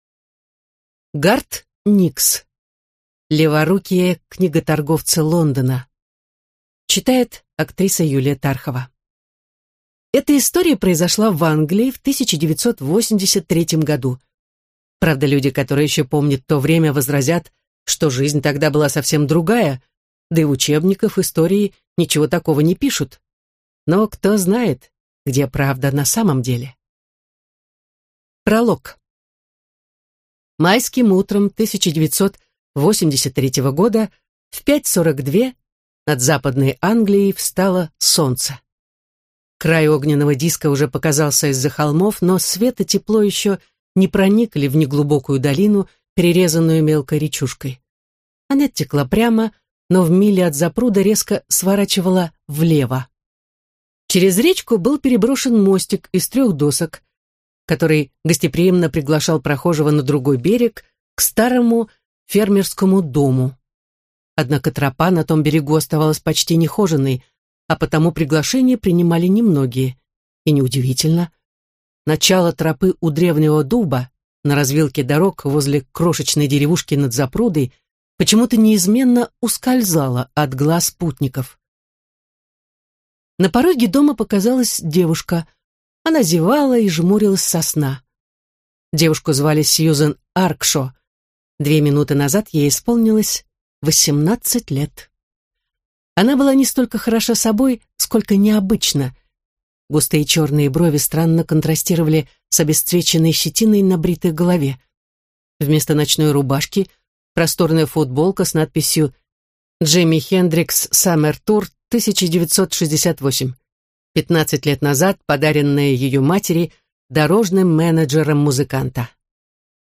Аудиокнига Леворукие книготорговцы Лондона | Библиотека аудиокниг